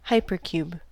Ääntäminen
US : IPA : /ˈhaɪ.pɚ.kjuːb/